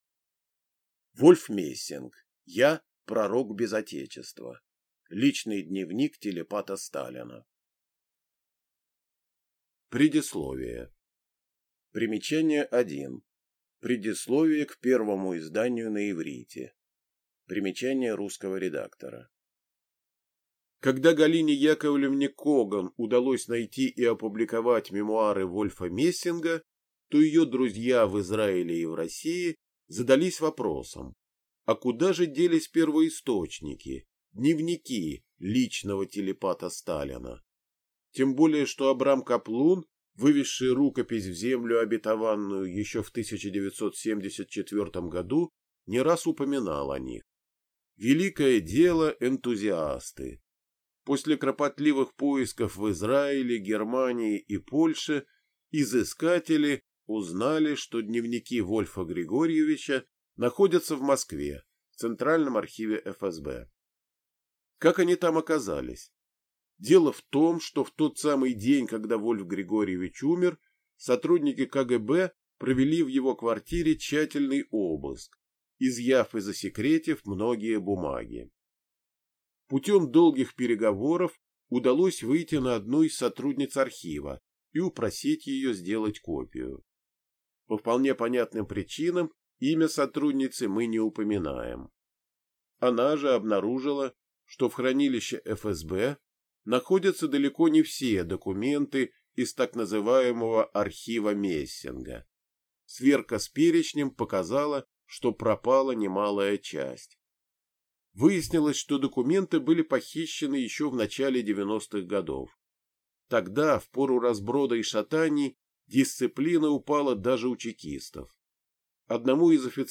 Аудиокнига Я – пророк без Отечества. Личный дневник телепата Сталина | Библиотека аудиокниг